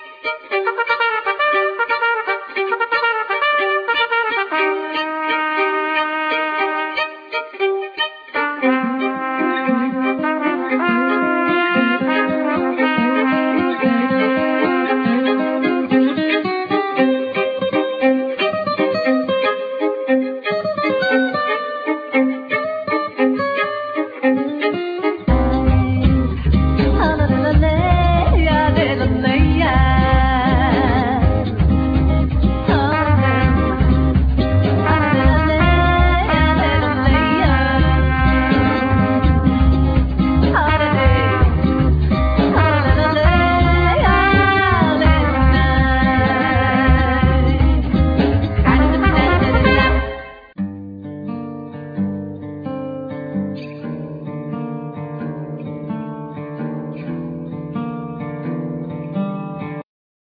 Violin,Vocal,Kazu
Ac.guitar
Trumpet
Doublebass
Drums,Percussions